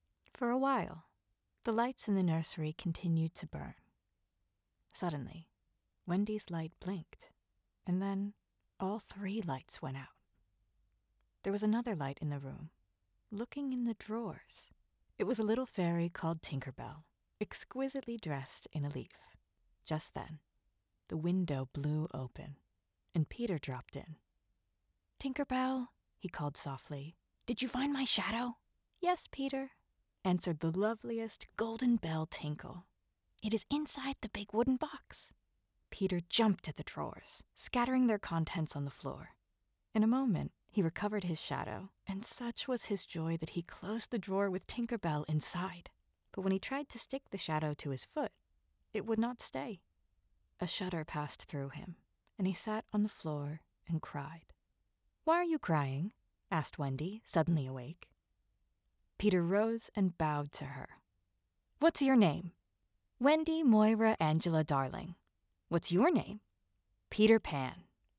Female
TEENS, 20s, 30s
Approachable, Confident, Conversational, Friendly, Reassuring, Upbeat, Warm
East Coast American (native), transatlantic
Microphone: Sennheiser mkh416